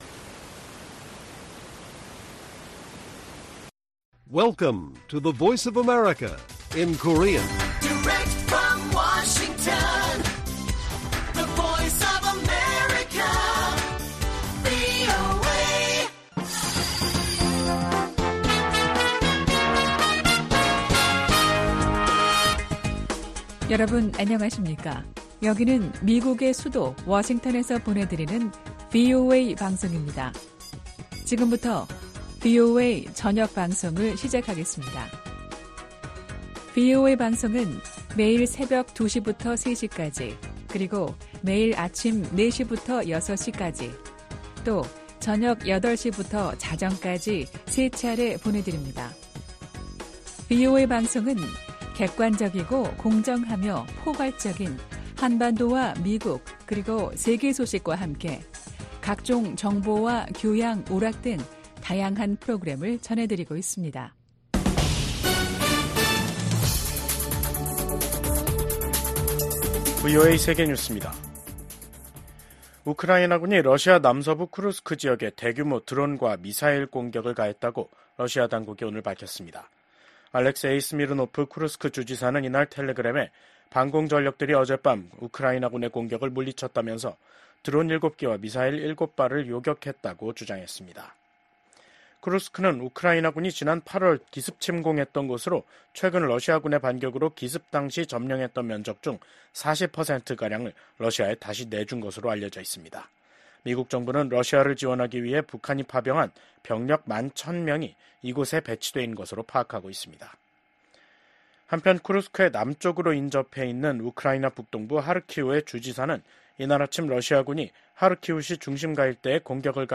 VOA 한국어 간판 뉴스 프로그램 '뉴스 투데이', 2024년 11월 25일 1부 방송입니다. 러시아 고위 당국자가 한국이 우크라이나에 살상무기를 공급하면 강력 대응하겠다고 경고했습니다. 미국 백악관 고위 관리는 북한과 러시아의 군사적 관계 강화가 차기 행정부에도 쉽지 않은 도전이 될 것이라고 전망했습니다. 미국은 북한이 7차 핵실험 준비를 마치고 정치적 결단만 기다리고 있는 것으로 평가하고 있다고 국무부 당국자가 말했습니다.